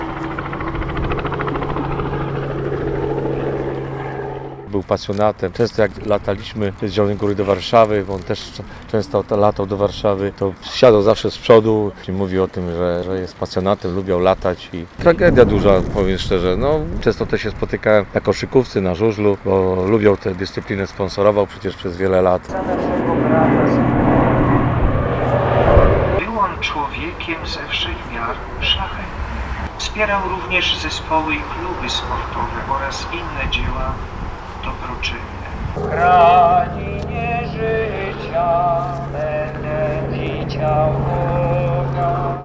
Podczas pogrzebu przyjaciele z Aeroklubu Ziemi Lubuskiej oddali mu hołd poprzez zrzucenie z helikoptera płatków kwiatów, dodatkowo kilkukrotnie honorowe rundy nad nekropolią wykonał samolot AZL: